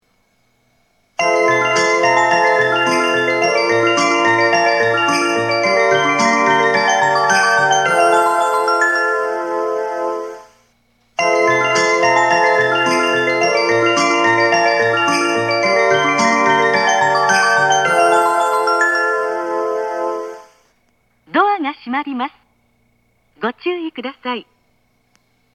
当駅の発車メロディーは音質が大変良いです。
発車メロディー
2コーラスです!3番線と同様、日中でも鳴りやすいです。